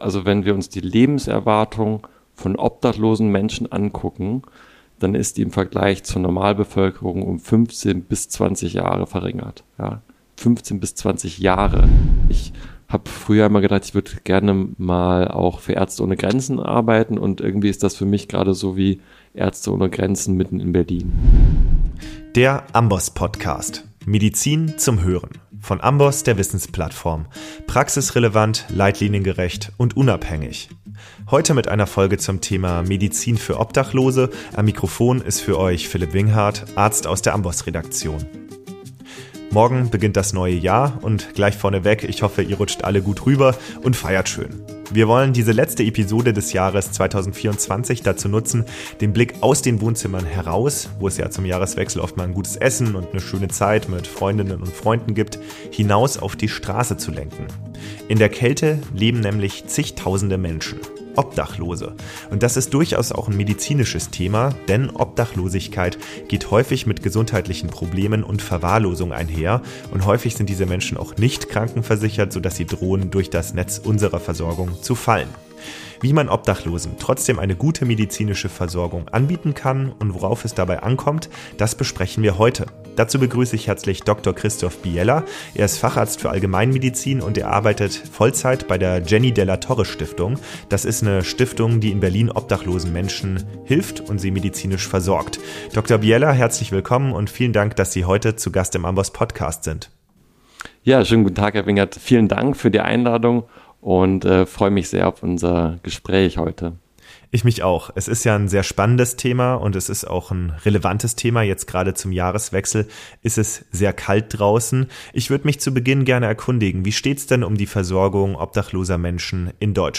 Die Wissensplattform AMBOSS macht mit diesem Podcast Medizin hörbar. Alle zwei Wochen sprechen wir mit Expert:innen über relevante Themen aus klinischem Alltag, Forschung und Gesundheitspolitik.